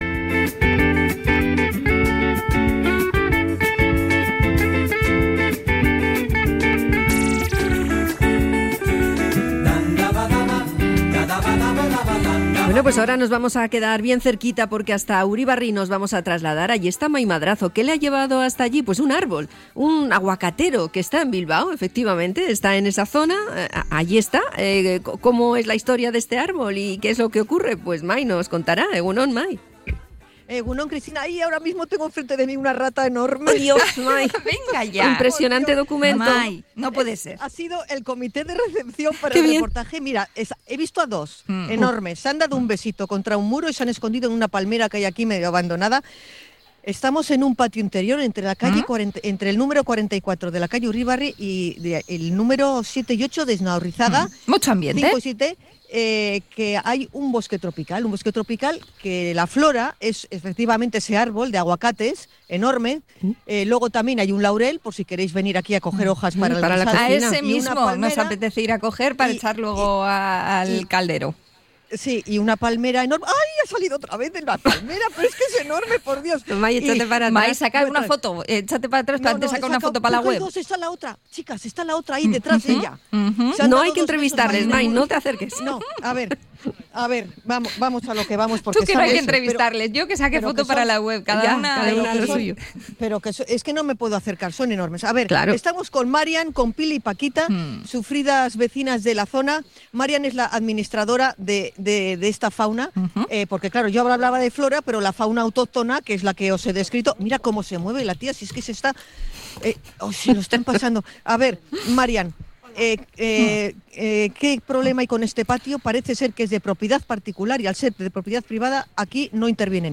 Hablamos con varias vecinas que denuncian problemas de salubridad derivados de un patio descuidado